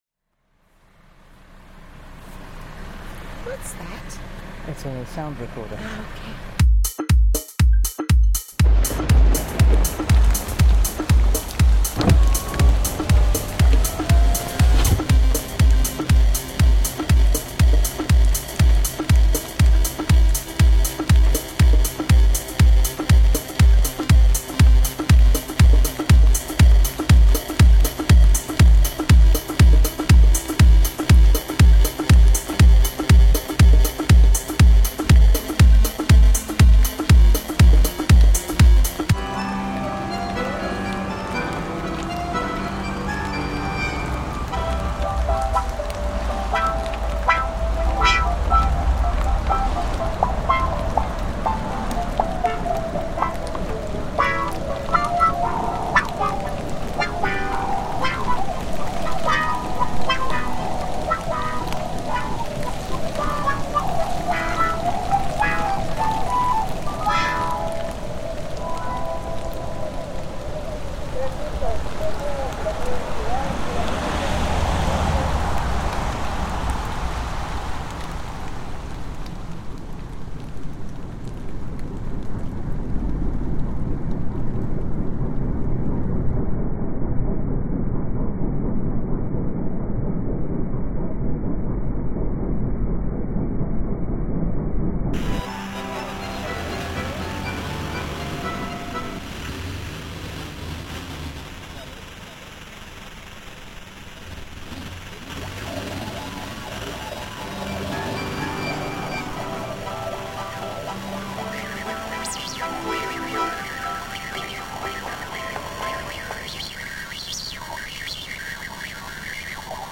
The sound of a 4x4 trapped in mud in Argentina, reimagined